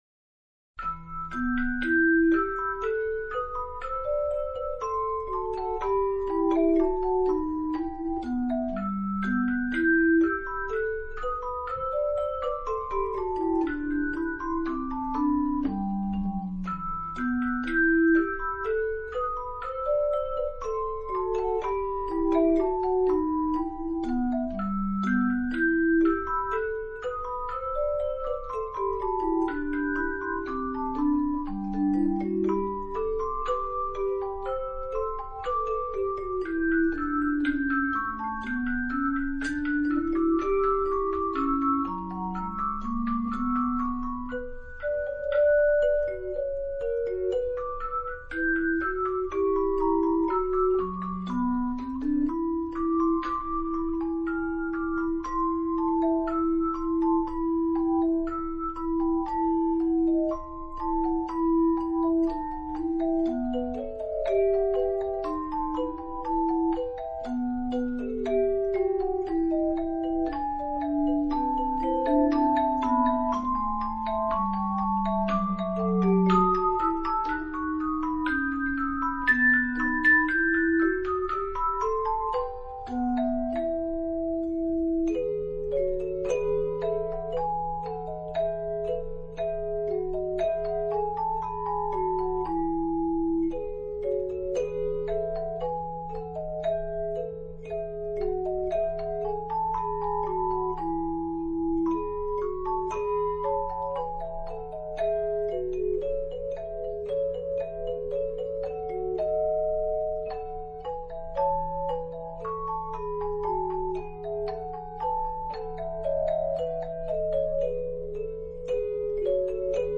トーンチャイムは普及型のハンドベルとして開発した楽器ですが、その美しい余韻と柔らかく心に沁み入るような音色は、ハンドベルとはまた違った魅力を持った楽器として愛されています。
♪ トーンチャイムの音を聴く